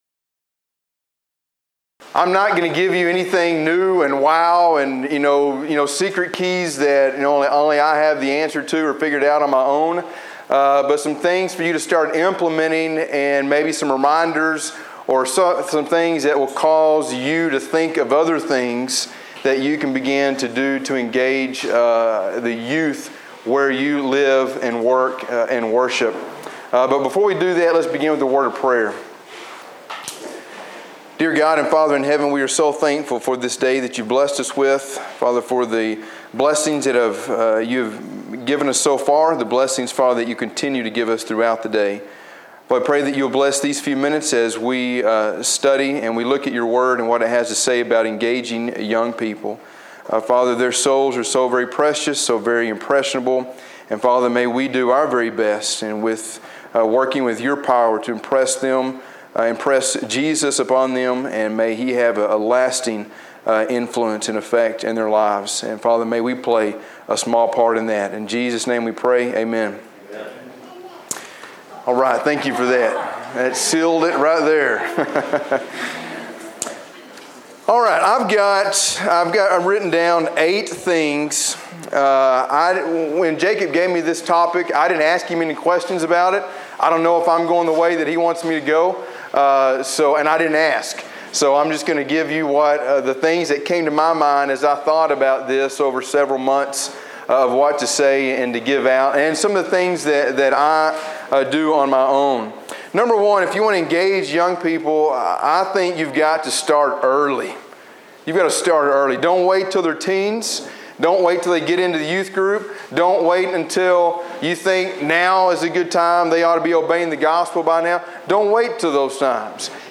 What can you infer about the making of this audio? Event: Discipleship U 2016